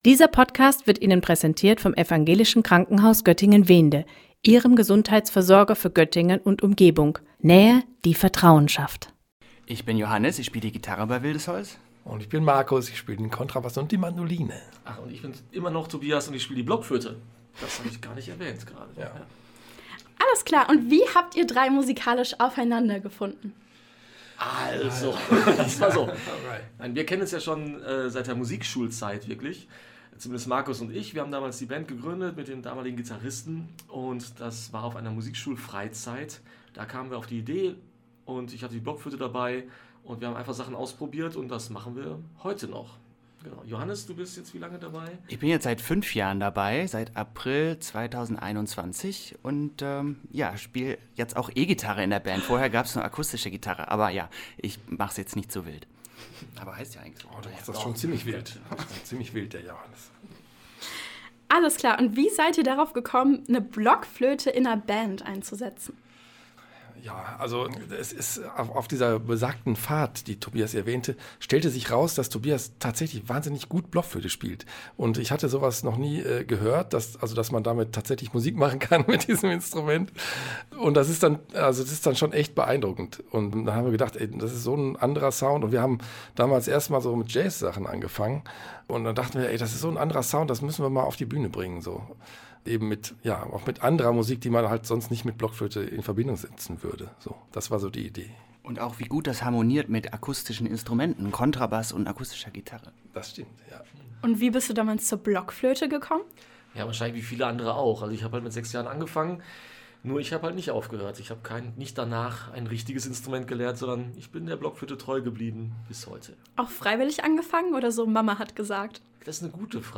Beiträge > Sounds on fire – Interview mit „Wildes Holz“ - StadtRadio Göttingen
WildesHolzInterviewfertig-playout.mp3